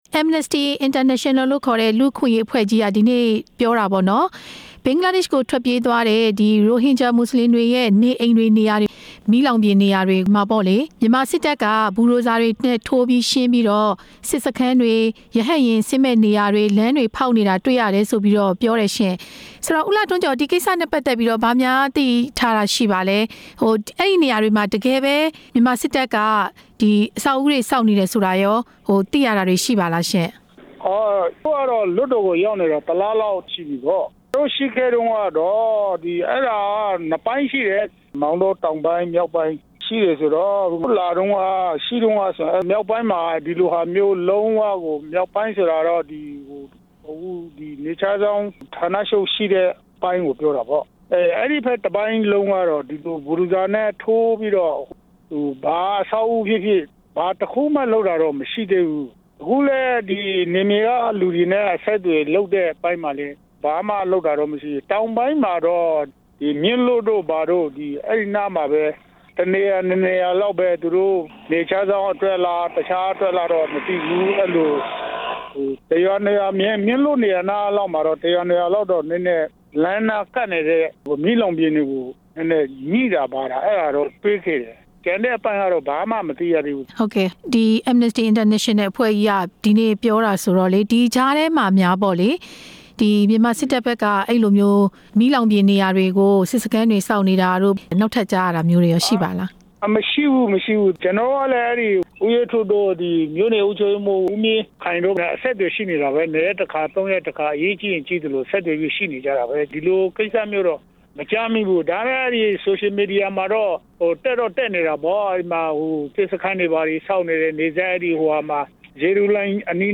AI အဖွဲ့ရဲ့ ပြောဆိုမှုအပေါ် ဒေသခံအမတ်နဲ့ မေးမြန်းချက်